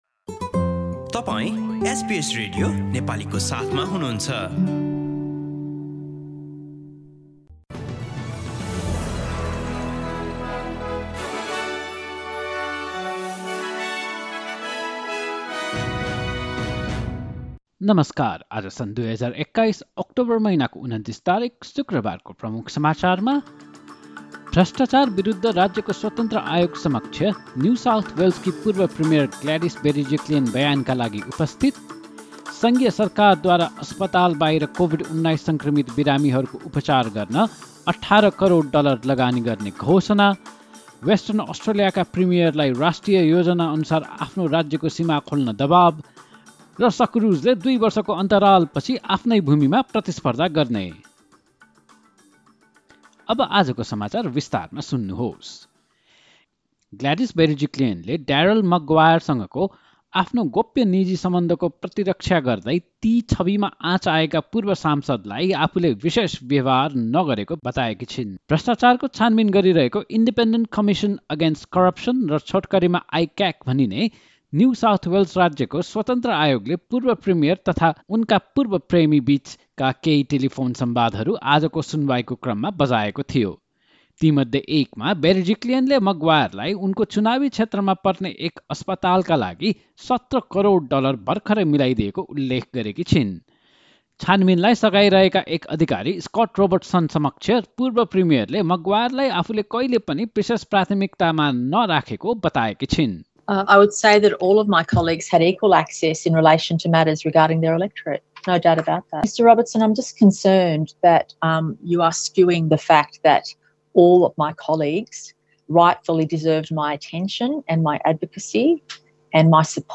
एसबीएस नेपाली अस्ट्रेलिया समाचार: शुक्रवार २९ अक्टोबर २०२१